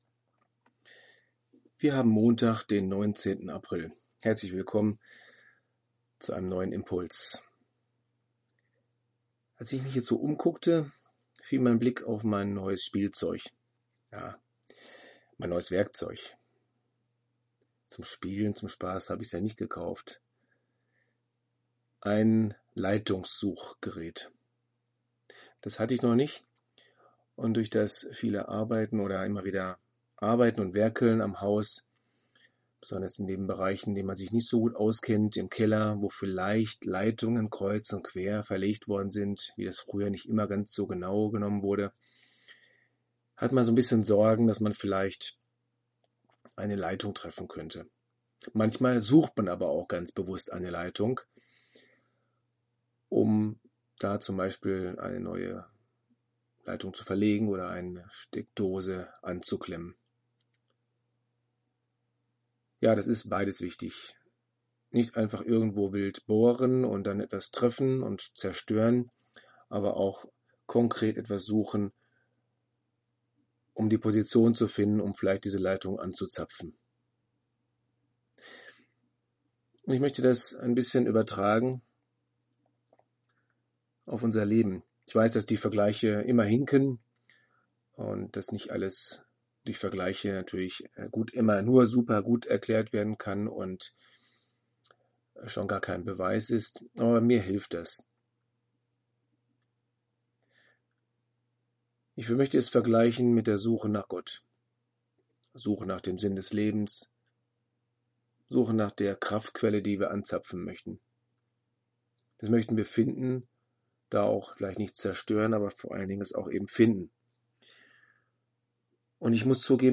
Audio-Andachten: Impulse, Denkanstöße über Gott und die Welt